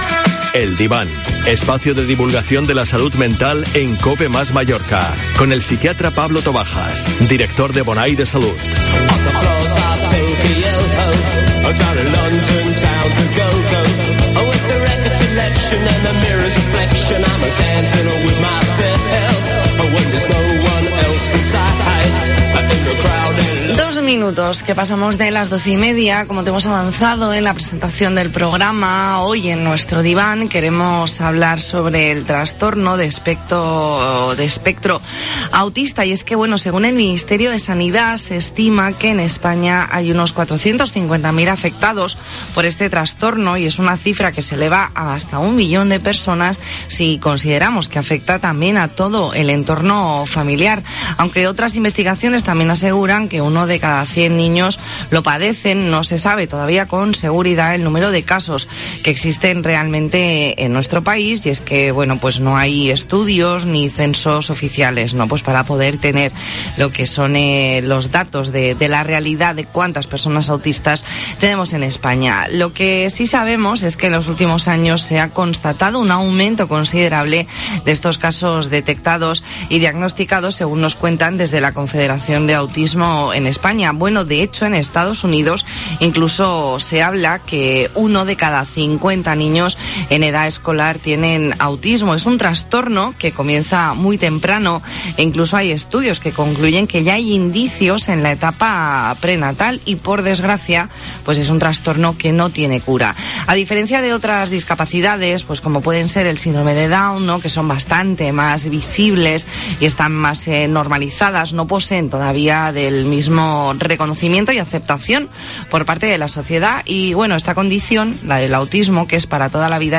Entrevista en La Mañana en COPE Más Mallorca, viernes 5 de febrero de 2021.